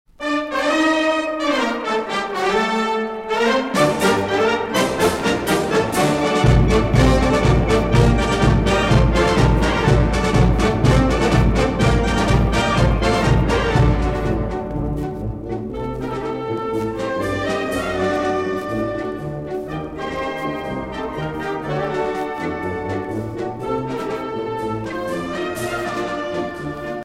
danse : paso-doble